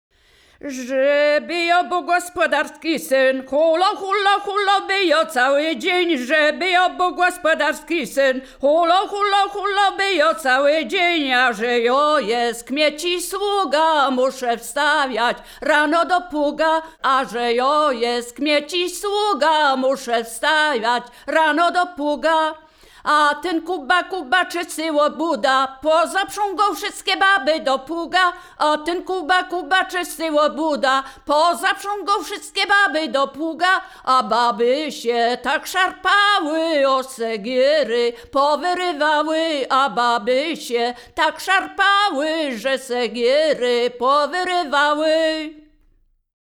województwo wielkopolskie, powiat gostyński, gmina Krobia, wieś Posadowo
żartobliwe przyśpiewki